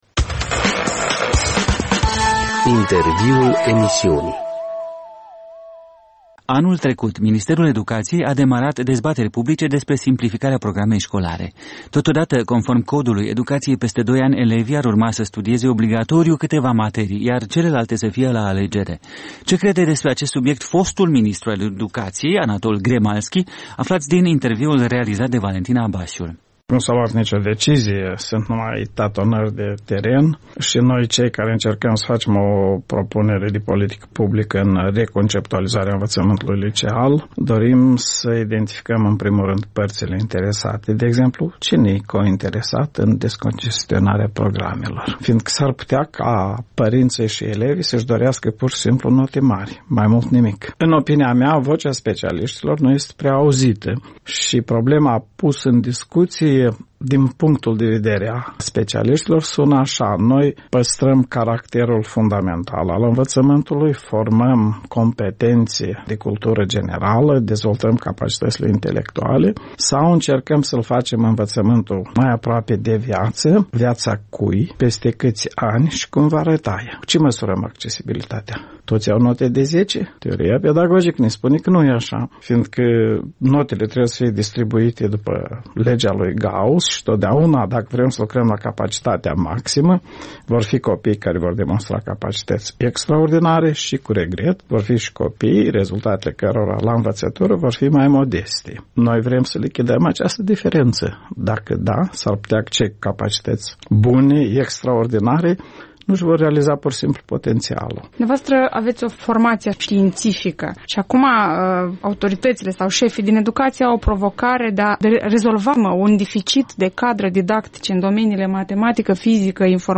Interviurile EL